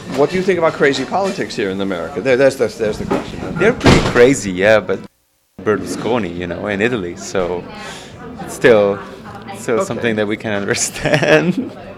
ITALIAN RESEARCHER IN PROVINCETOWN, MASSACHUSETTS COFFEE SHOP SAYS UNITED STATES POLITICS IS “CRAZY” AS IT IS IN ITALY (CUT INCLUDES TECHNICAL PROBLEM)
MOS-ITALIAN-RESEARCHER-IN-PROVINCETOWN-COFFEE-SHOP-SAYS-US-CRAZY-POLITICS-IS-LIKE-ITALY.mp3